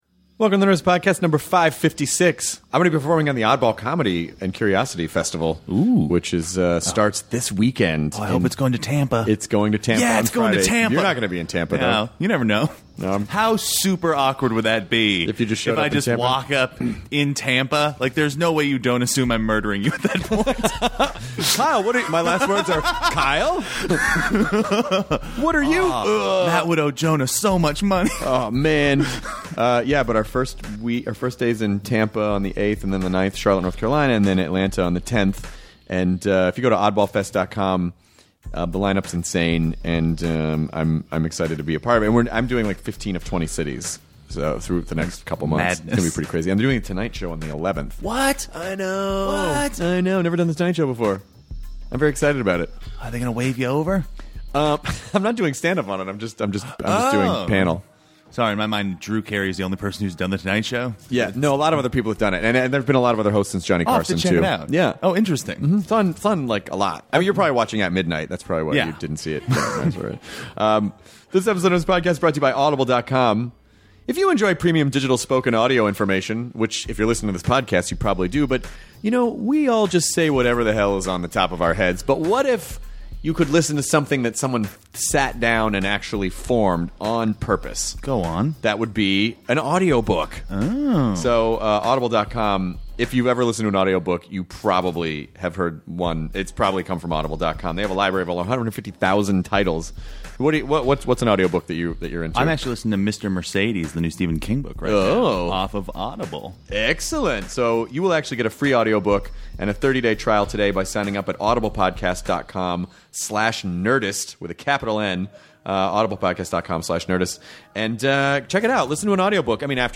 The talented Alicia Witt chats with Jonah and Chris about vegan dairy options, they talk about all the possible sequels to Mr. Holland’s Opus, how she got into music and her process of songwriting and what it’s like working on Justified!